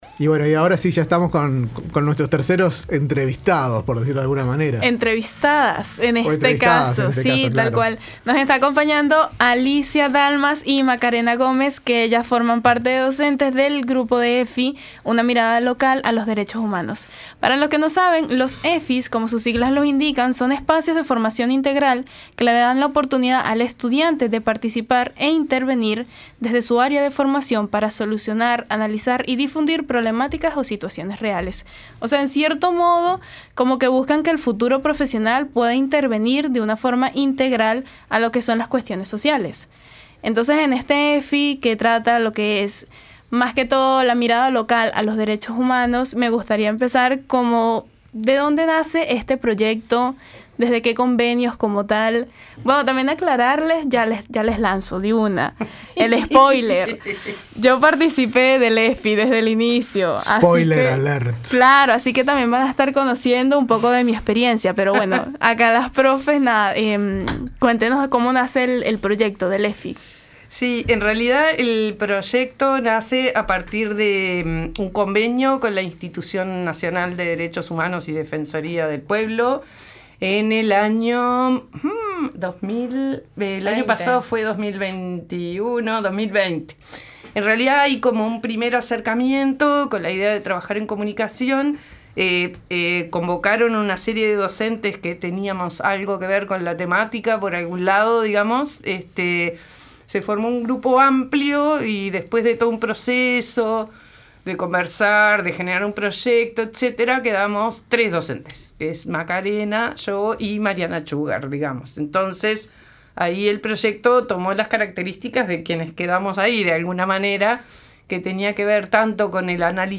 Un programa hecho por y para estudiantes.